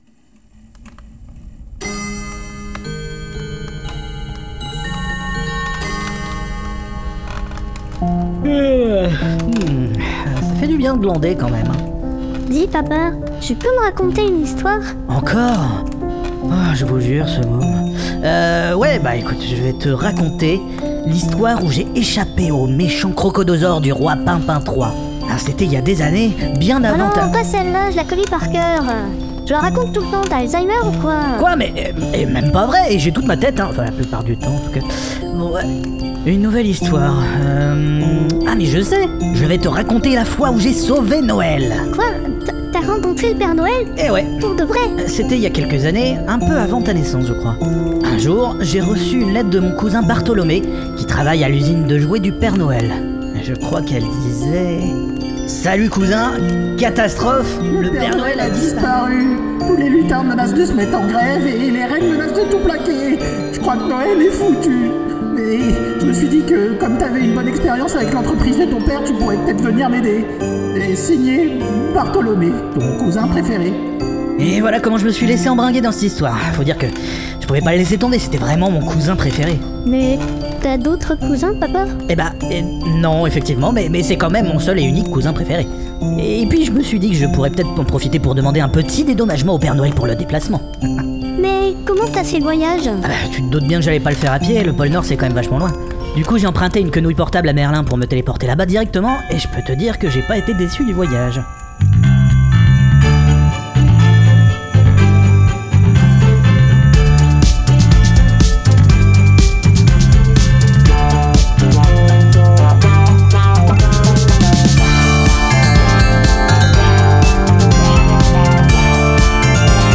La fiction audio "Les Contes de Fitzgerald" revisite donc les contes de notre enfance de manière parfaitement irrévérencieuse, portée par un humour absurde et déluré, et un casting vocal aux petits oignons.